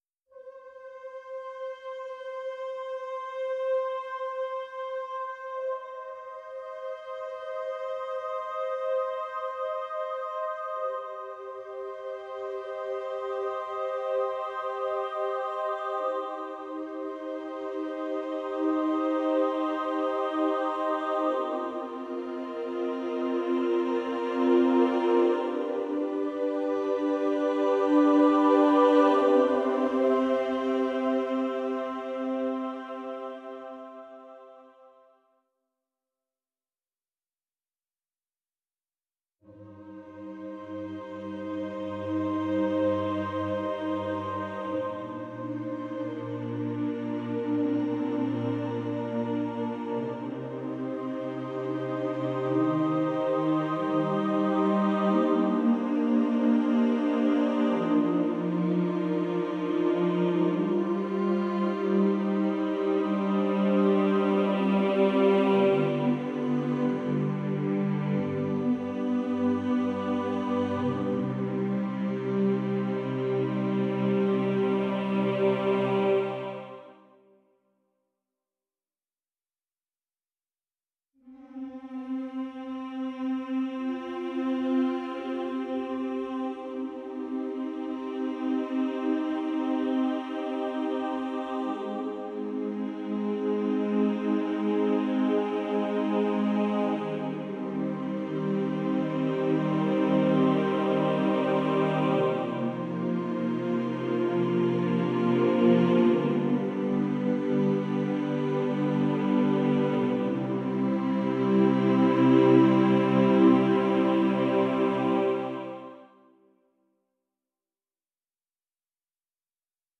Title Ave Maria Opus # 524 Year 2021 Duration 00:02:47 Self-Rating 5 Description SATB a capella, Largo. It would work without words (per the mp3). mp3 download wav download Files: mp3 wav Tags: Choral, A Capella Plays: 2996 Likes: 34
524 Choral - Ave Maria.wav